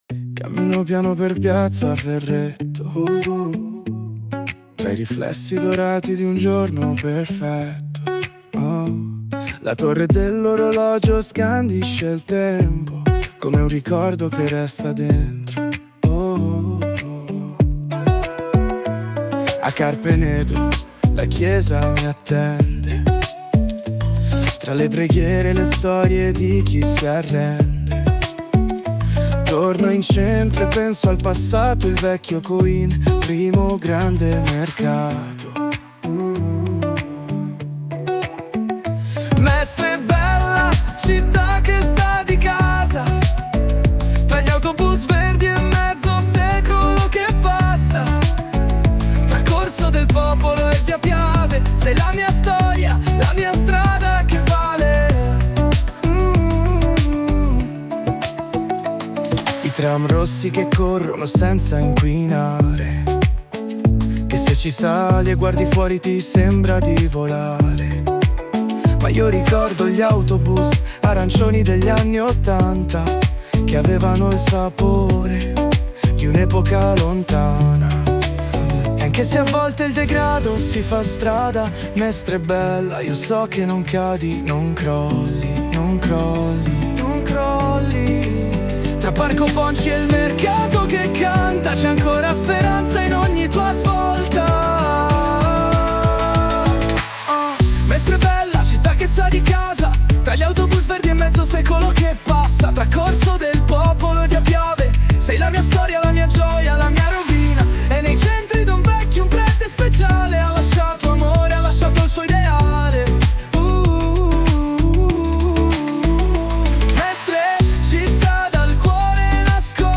Melodia, esecuzione e canto realizzati con l'intelligenza artificiale (Suno).